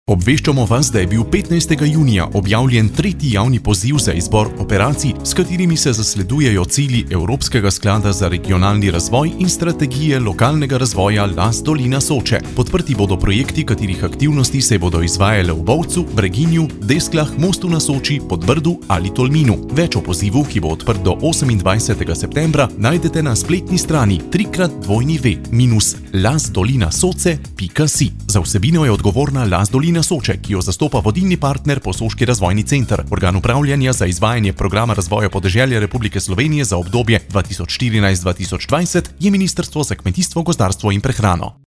Radijski oglas - 3. javni poziv LAS - Las Dolina Soče_do 2020